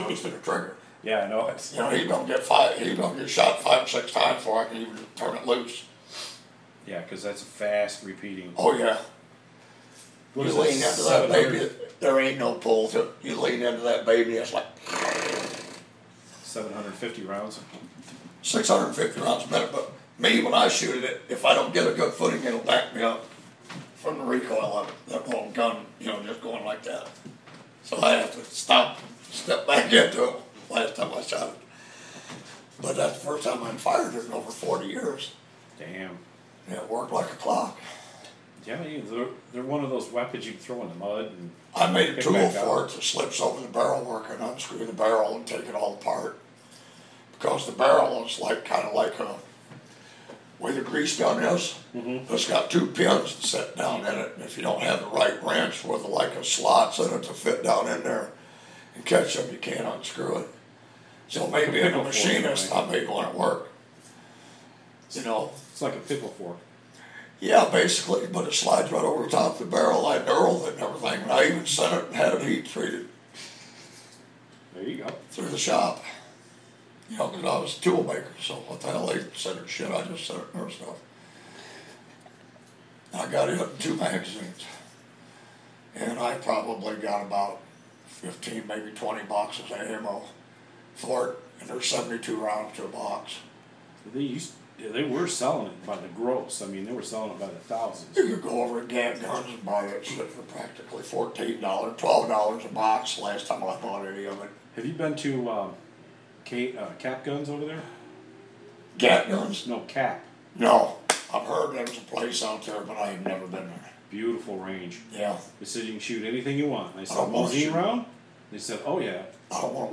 After Memorial Weekend, a former Marine & Vietnam Vet stops by on his way home. Sharing stories, talking about the war and of course guns.